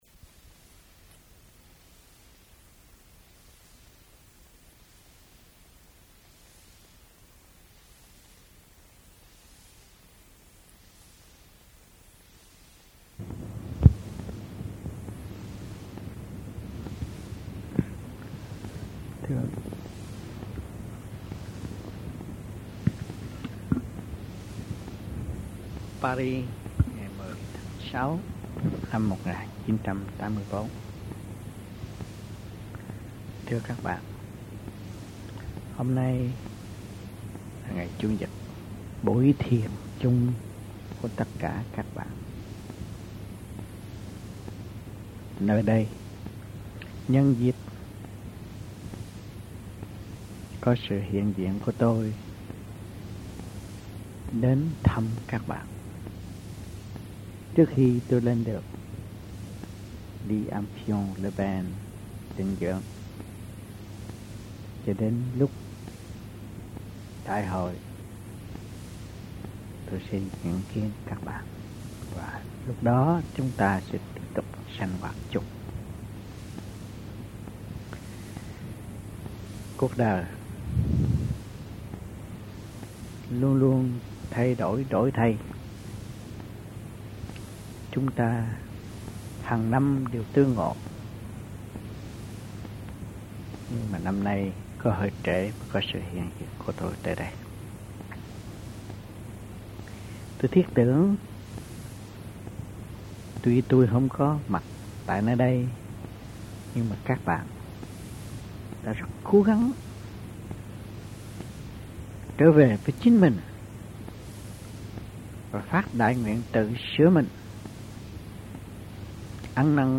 Địa danh : Paris, France
Trong dịp : Sinh hoạt thiền đường